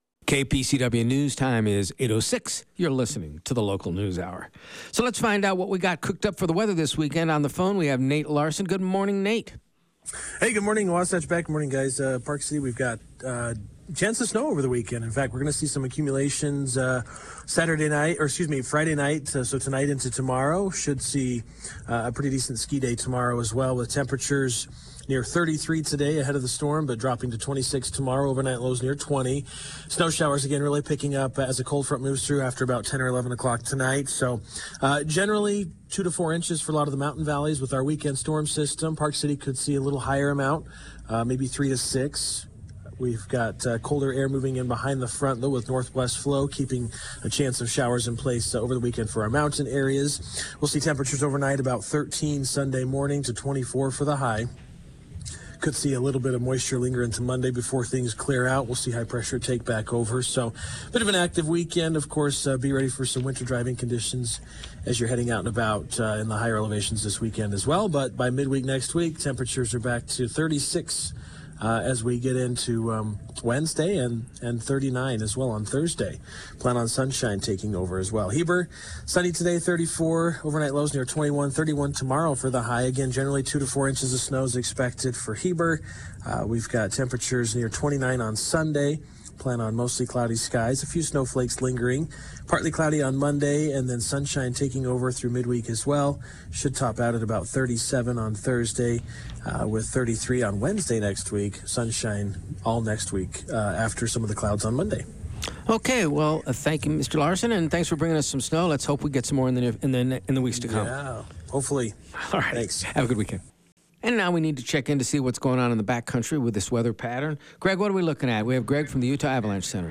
Get the information directly from the people making news in the Wasatch Back with live interviews every weekday.